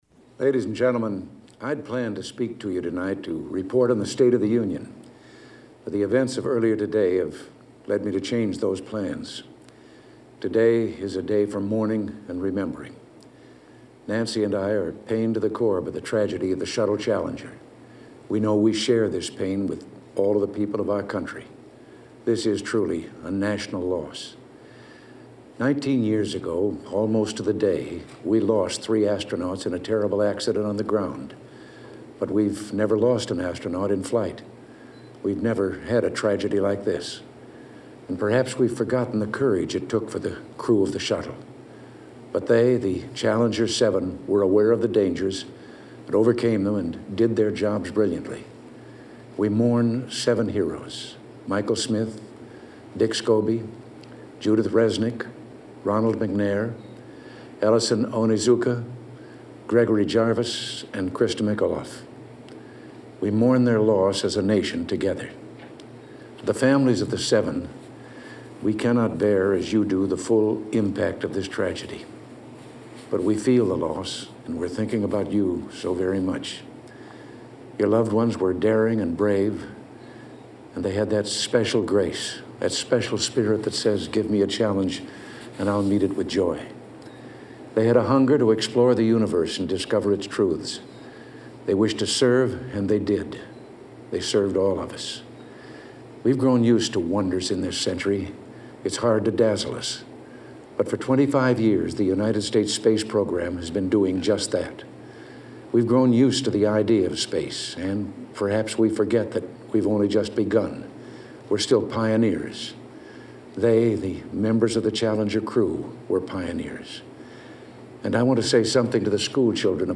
Presidential Speeches
President Reagan gives this address to the nation from the Oval Office on an evening scheduled for the State of the Union address.